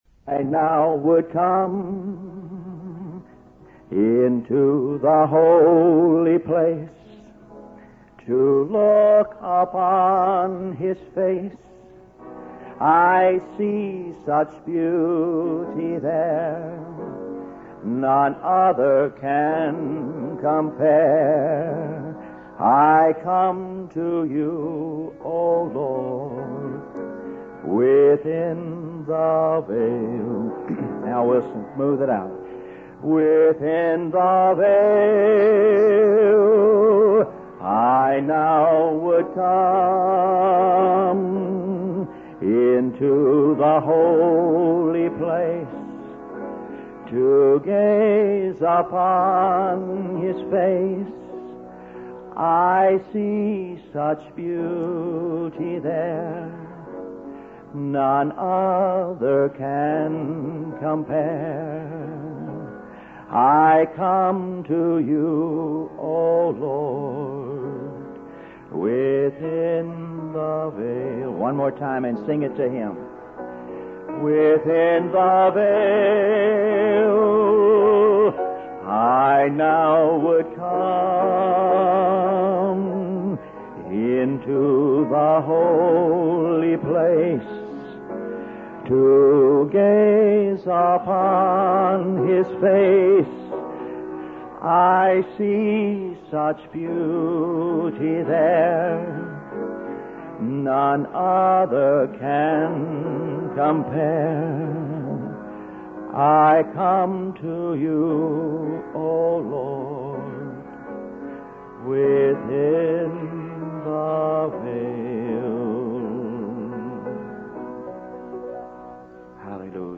In this sermon, the speaker addresses a faithful congregation and expresses gratitude for their presence.